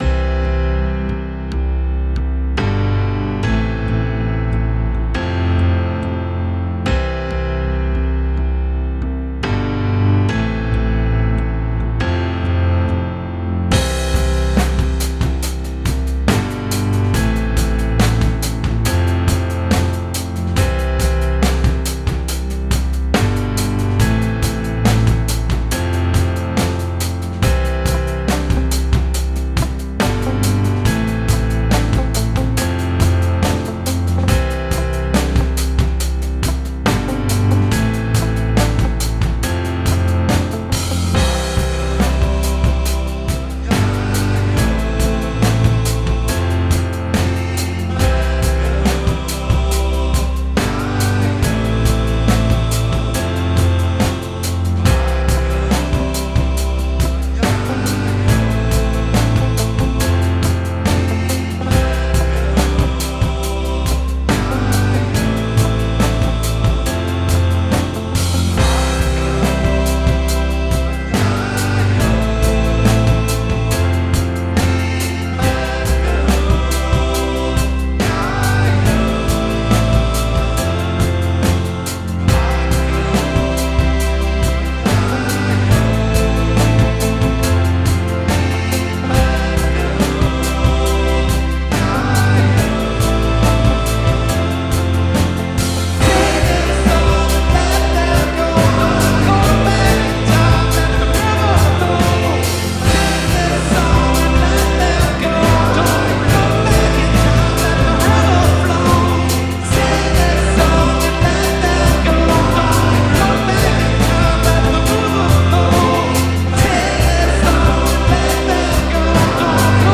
hier die idee, die ich vorletzte woche auf dem rechner gebastelt und improvisiert eingesungen hatte.
war ein bisschen afro-soulig geworden
da muss aber noch viel dran gemacht werden. hauptgesang und arrangement fehlen, etc.
Schöner Trick mit den unterschiedlich langen Chords.
Sture Drums, die Orientierung geben und dann immer mehr Stimmen und Gegenlinien dazu. Mit Mute-Gitarre, Piano, Orgel, Strings, Bläser. Ich glaub, das waren so in etwa alle Instrumente, die es auf der Welt gibt :) Zieht gewaltig auf.
generic-soul-song-short.mp3